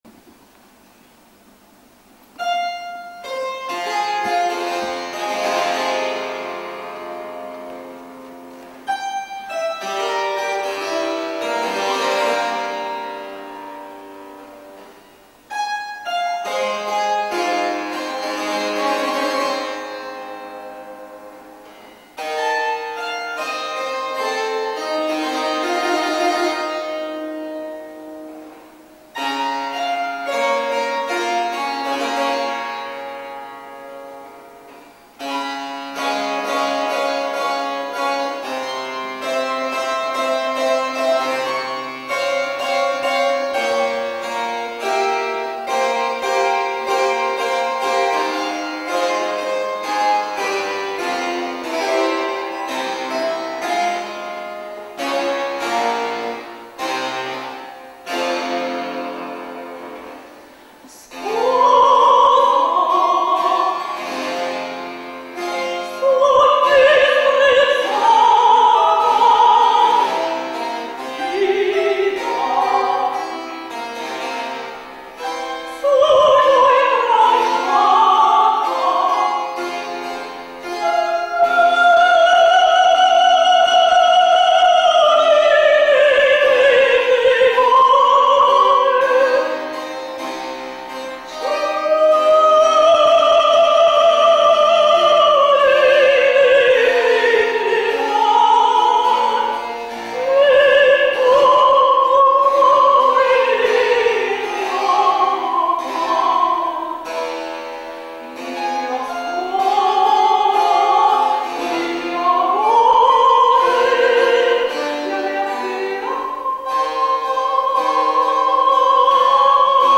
Исполняет солист филармонического отдела Москонцерта,
сопрано